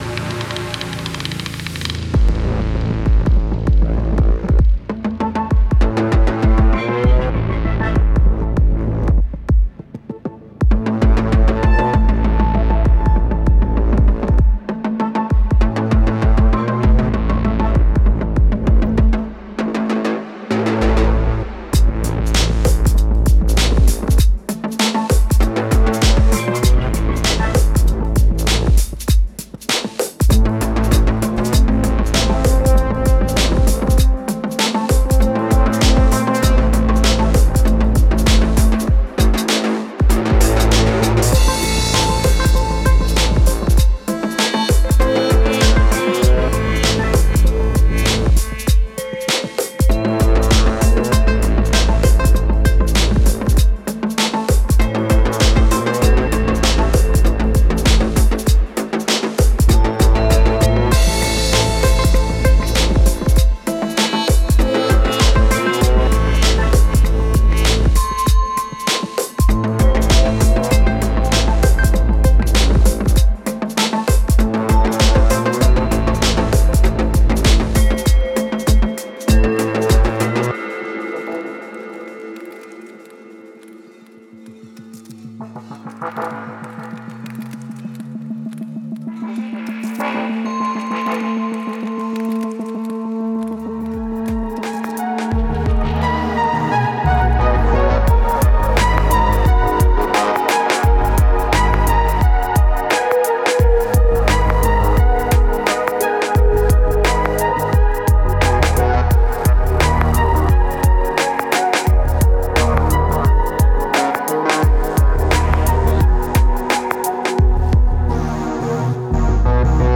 Electronica, Experimental, Psy
Это демо не отмастерено инженерами.
Мне кажется что слишком много саба и есть проблемы в пространстве.
Разумеется всё компрессировалось, максимизировалось и лимитировалось и на рисунке динамики треков видны срезанные пики.